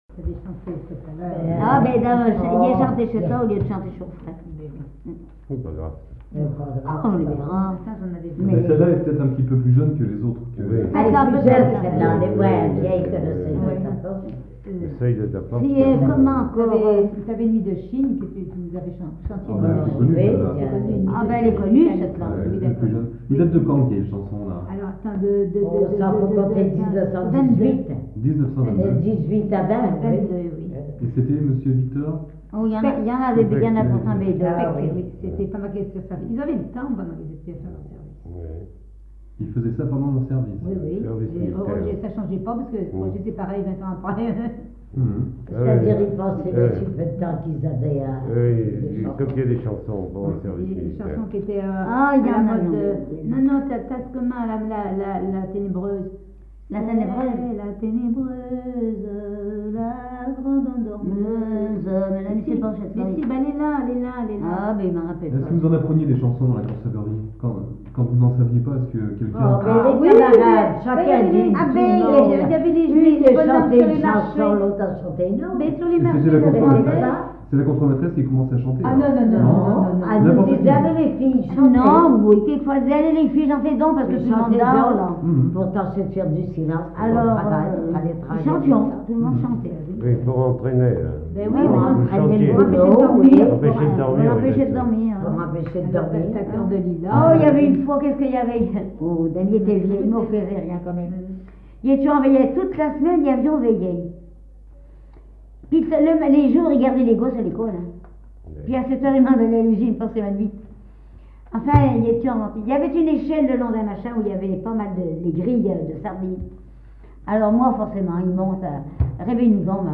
chanteur(s), chant, chanson, chansonnette
témoignages sur le poissonnerie et chansons
Catégorie Témoignage